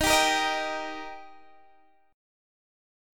Eb Chord
Listen to Eb strummed